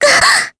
Jane-Vox_Damage_jp_03.wav